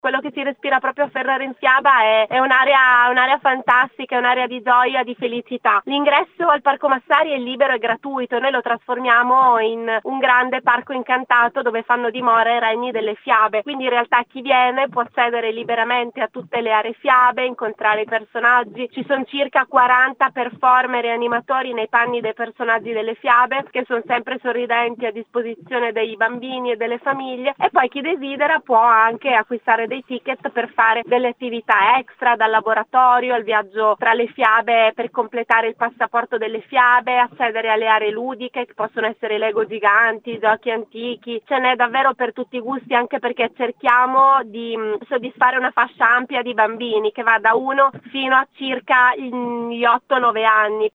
Ferrara in Fiaba 2024 | Radio Bruno